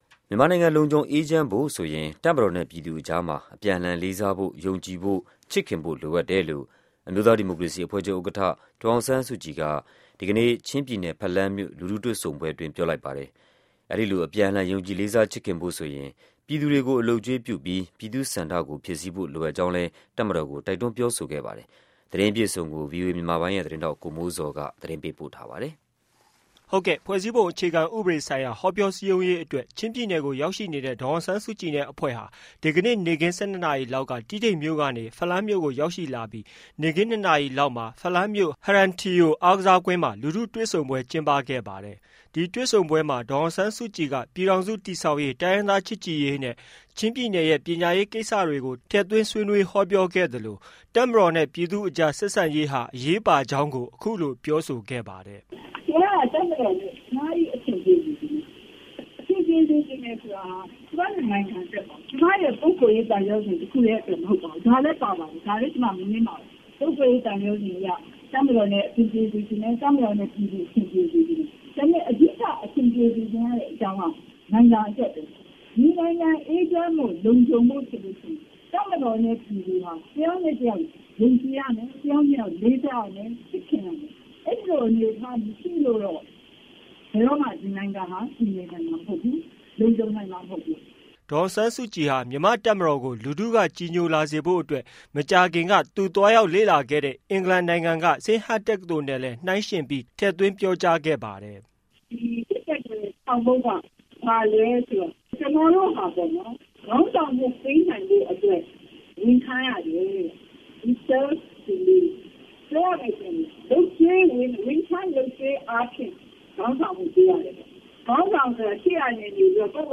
ဒေါ်စုရဲ့ ဖလမ်းမြို့လူထုတွေ့ဆုံပွဲမိန့်ခွန်း